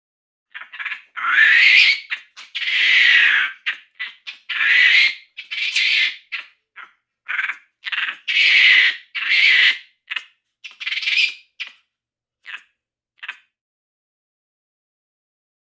Monkey, Capuchin Growls. Loud, High Pitched Scream With Chatter Sound. Close Perspective.